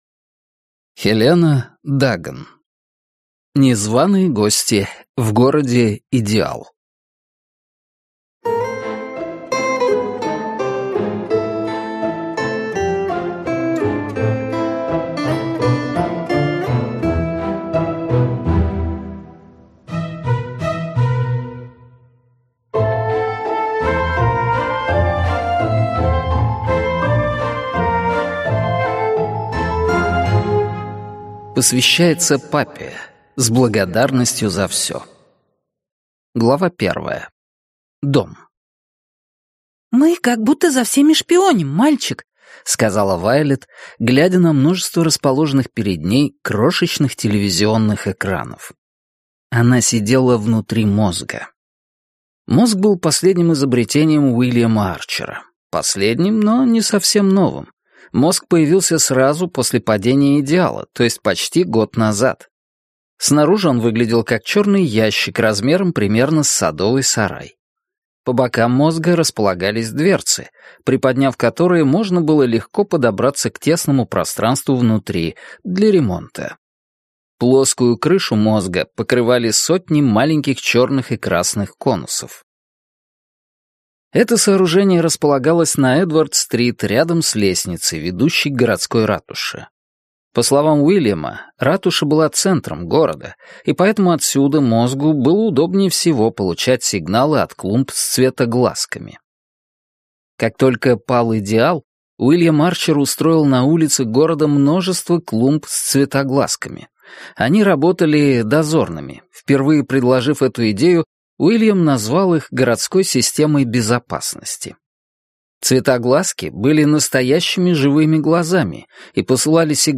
Аудиокнига Незваные гости в городе Идеал | Библиотека аудиокниг